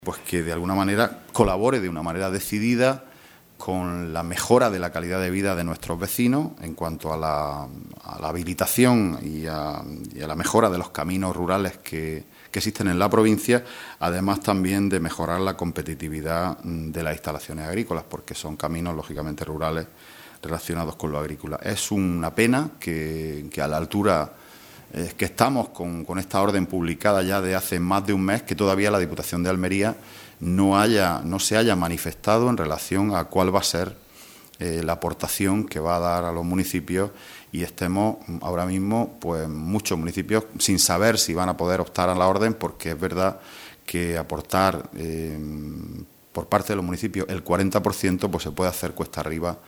Rueda de prensa que ha ofrecido el portavoz socialista en la Diputación Provincial, Juan Antonio Lorenzo